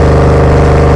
Engine